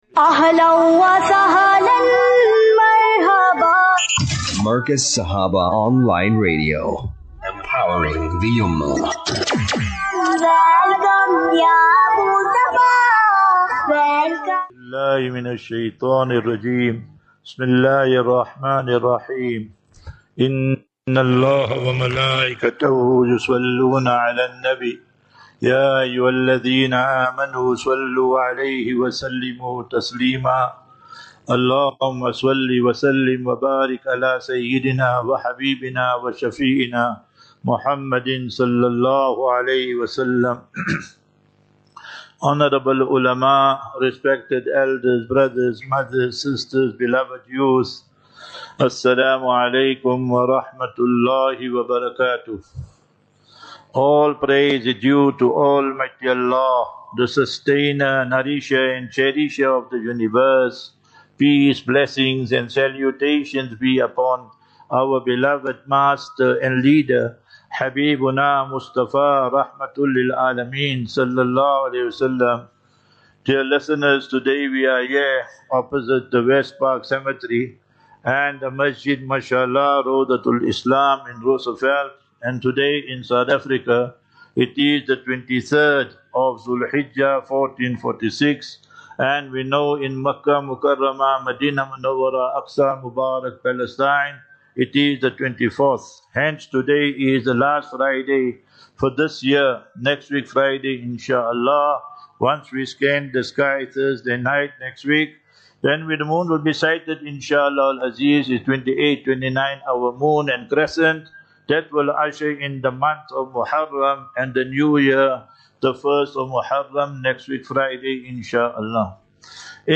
20 Jun 20 June 25 - Jumu,ah Lecture at Raudhatul Islam Masjid (Roosevelt Park, JHB)